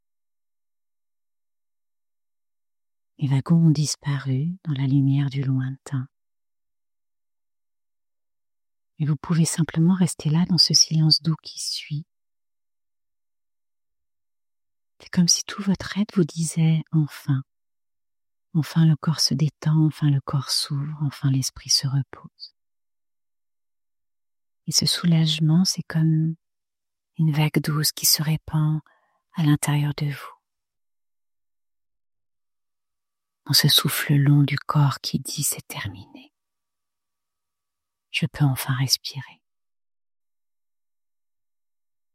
Un programme d’hypnose en 3 étapes pour apaiser le stress et retrouver un sommeil naturel